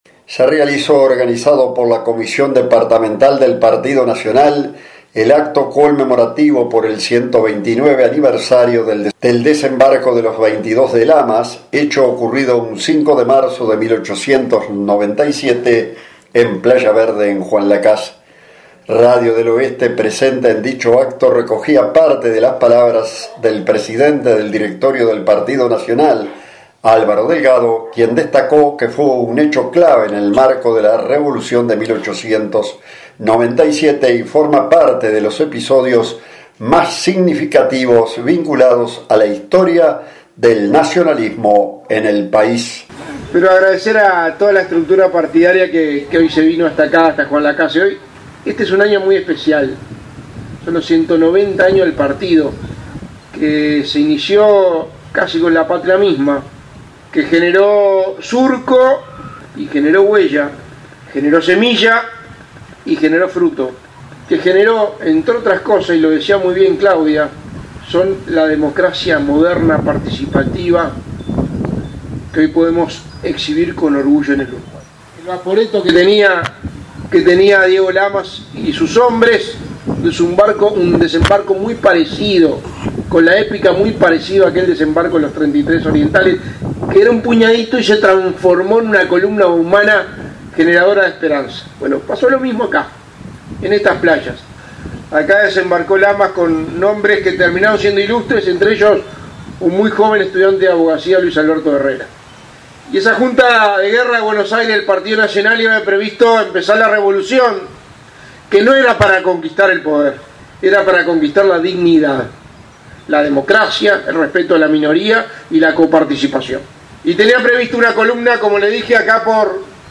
Organizado por la Comisión Departamental del Partido Nacional, se realizó el acto conmemorativo por el 129° aniversario del Desembarco de los 22 de Lamas, hecho histórico ocurrido el 5 de marzo de 1897 en Playa Verde de Juan Lacaze.
La actividad contó con la presencia de dirigentes y militantes nacionalistas, y fue seguida por Radio del Oeste, que recogió parte de las palabras del presidente del Directorio del Partido Nacional, Álvaro Delgado.